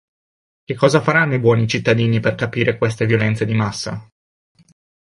Pronunciado como (IPA)
/ˈkwes.te/